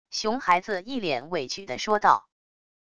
熊孩子一脸委屈的说道wav音频